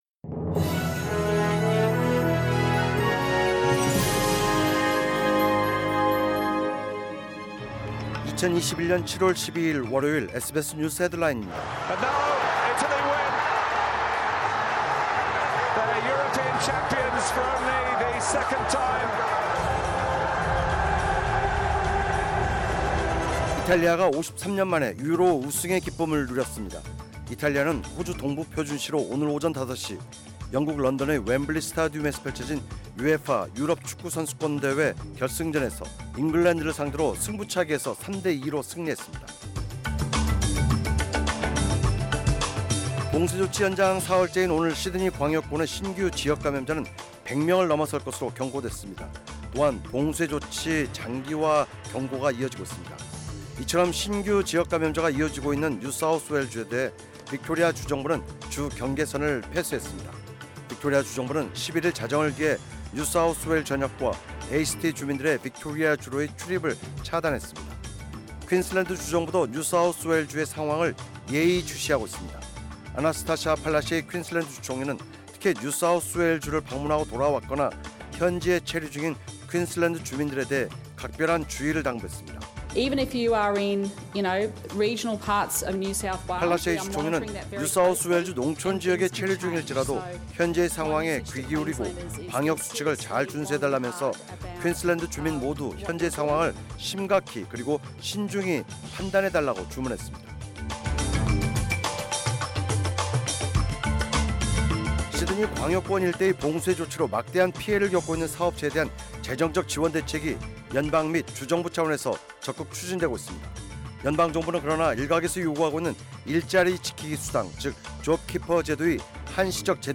2021년 7월 12일 월요일 오전 SBS 뉴스 헤드라인입니다.